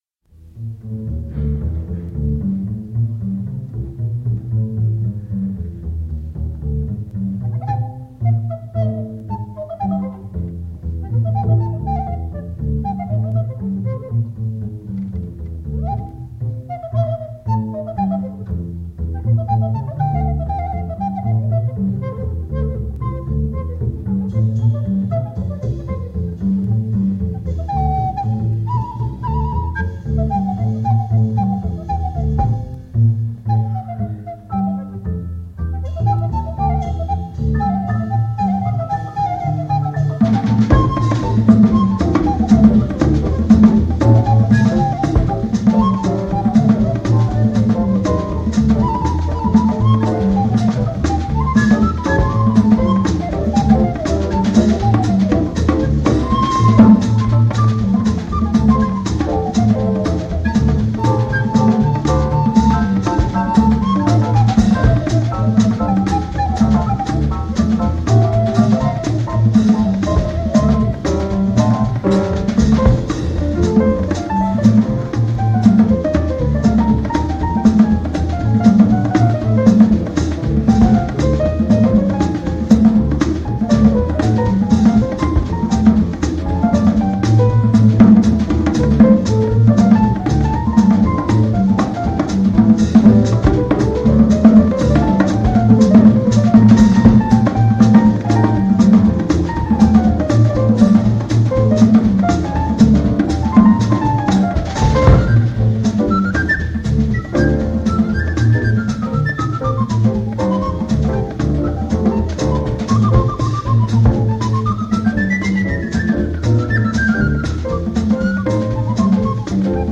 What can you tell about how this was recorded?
on the recorder) at a jazz club in 1953